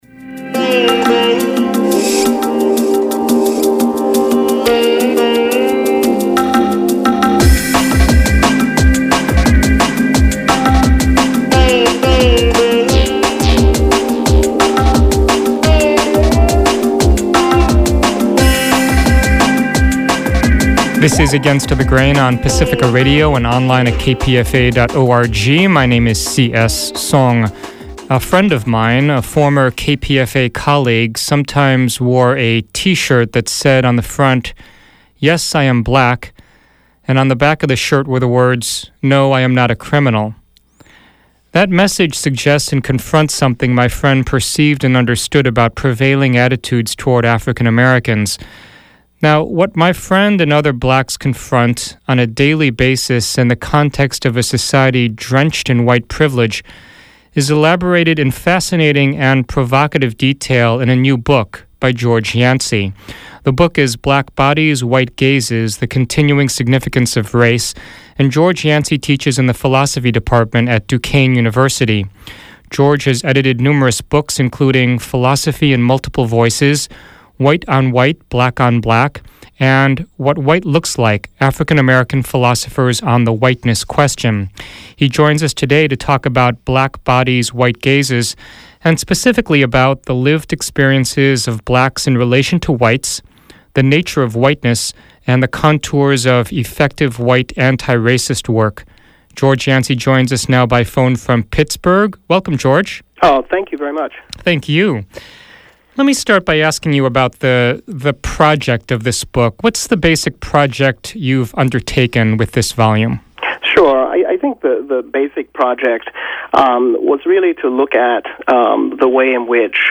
Against the Grain 20-20 Collection: 20 Interviews Celebrating 20 Years